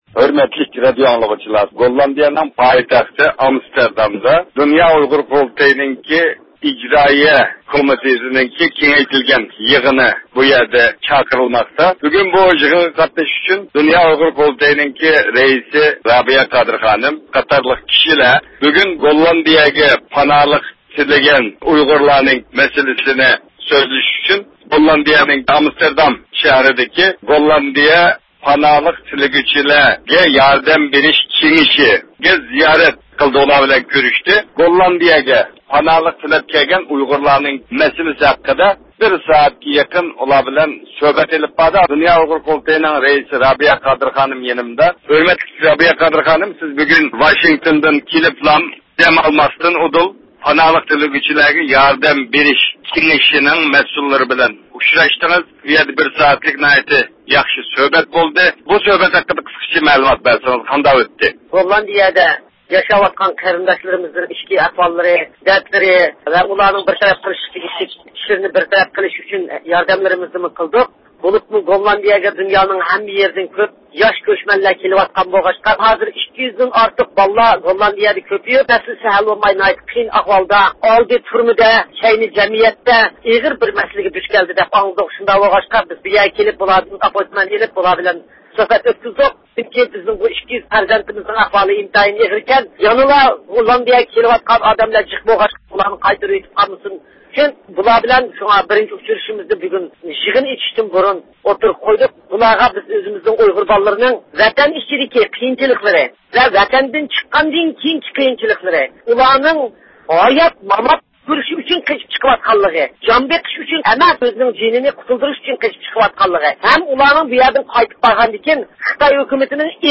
بۇ كۆرۈشۈش ھەققىدە تېخىمۇ تەپسىلىي مەلۇماتلارغا ئىگە بولۇشنى خالىسىڭىز، دىققىتىڭىز رابىيە قادىر خانىم بىلەن ئېلىپ بارغان سۆھبىتىمىزدە بولسۇن.